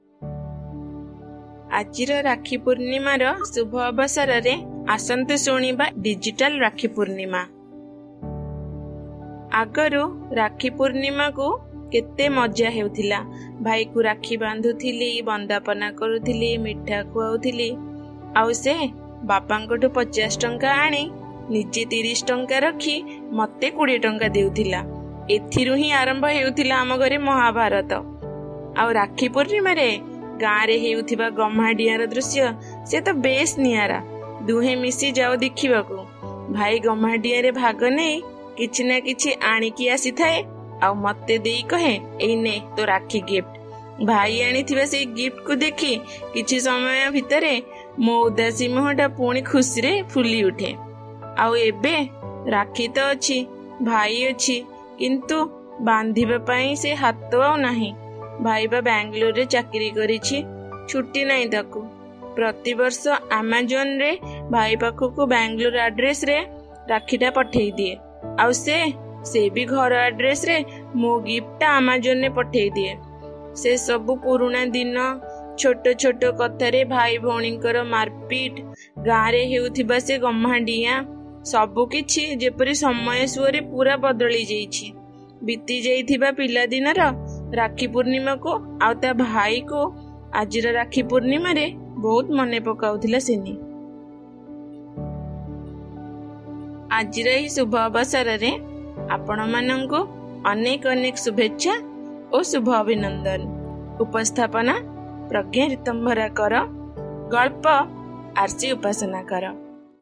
Odia Stories